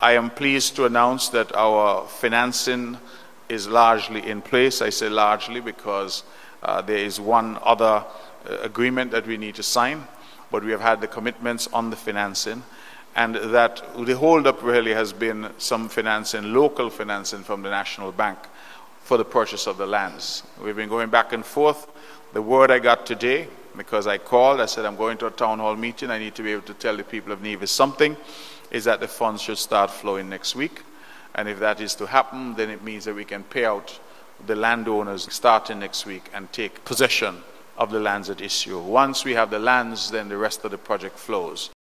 The first in a series of town hall meetings was held on Monday, June 30th 2025, at the Pond Hill Community Centre, in the St. John’s Constituency.
Premier Brantley also shared this information on the airport project: